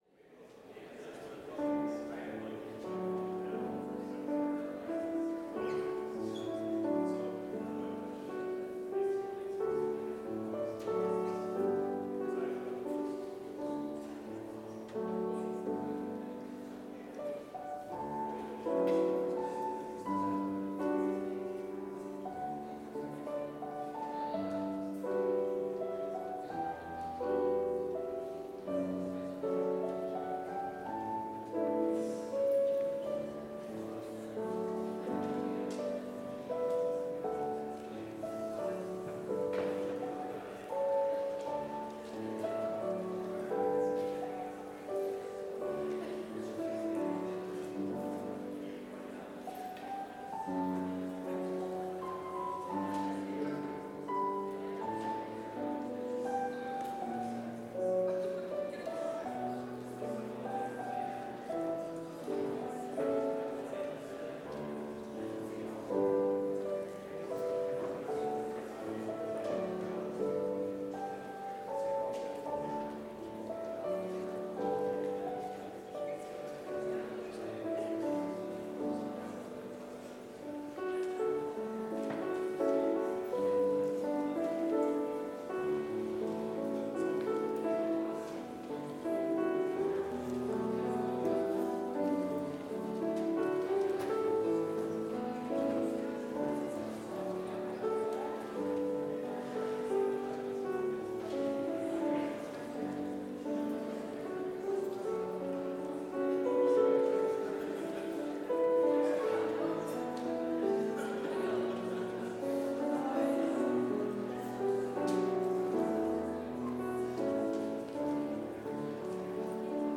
Complete service audio for Chapel - Wednesday, December 11, 2024